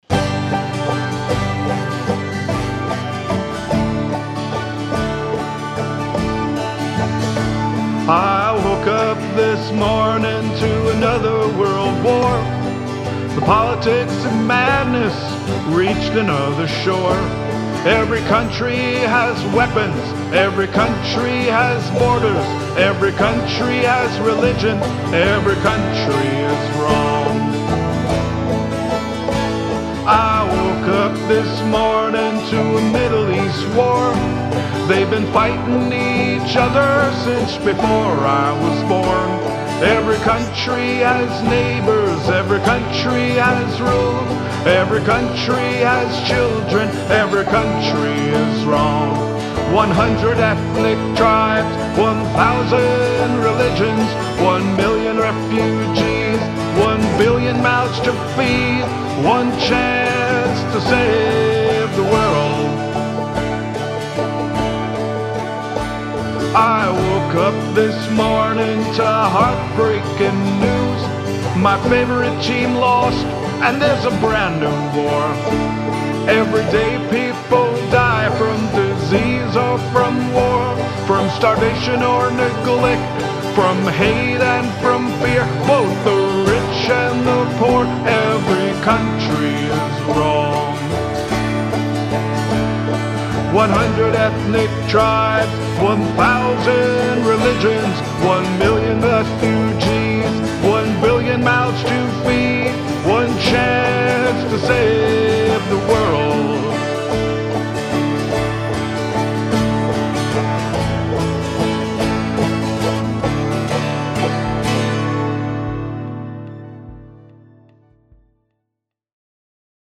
Newest 100 Other Songs banjo songs which Banjo Hangout members have uploaded to the website.